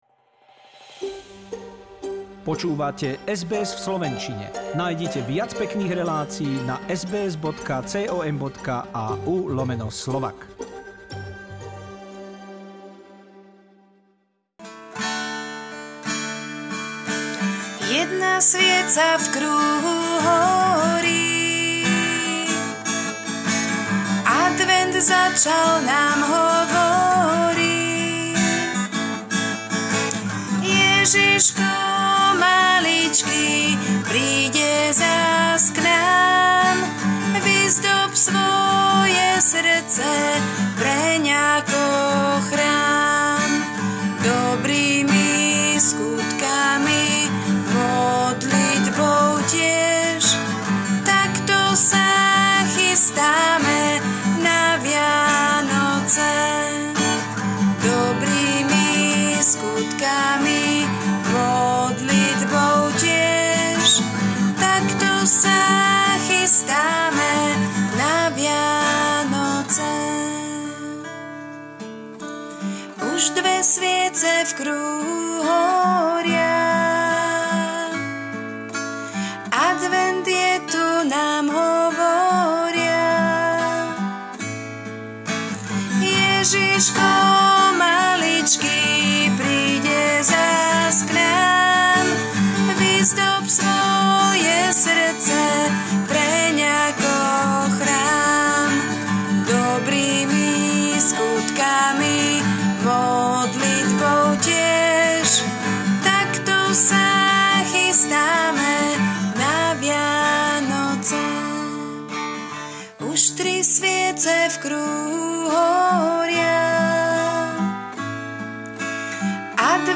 Začal sa advent. Dejiny, význam, príhovor kňaza a duchovné piesne